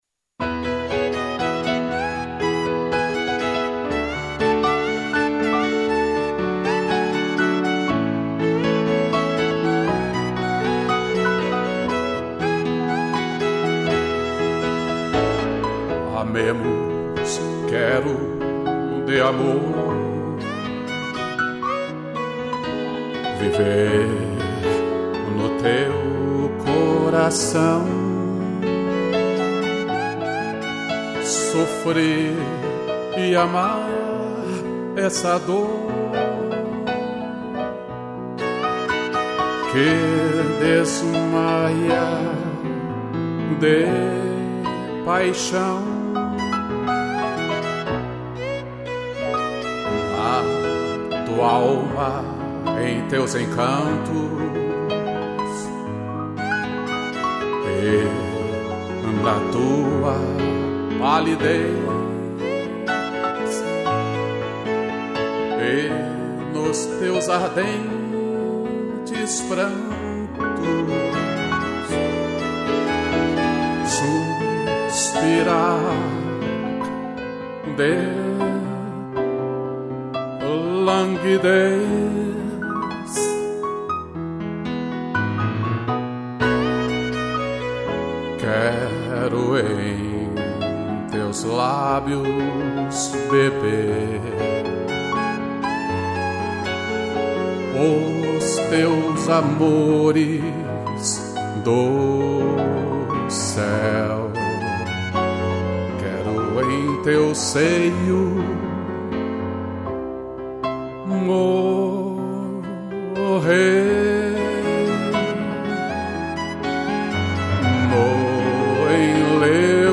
2 pianos e violino